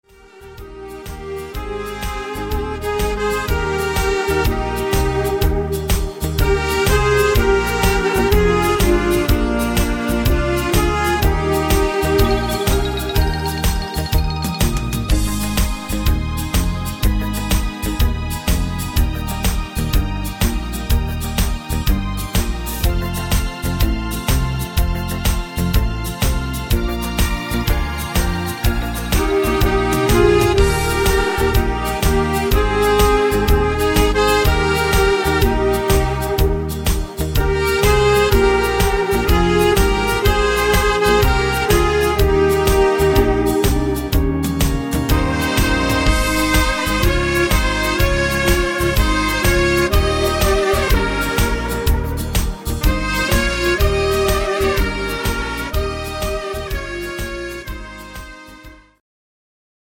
Medley (instr. Saxophon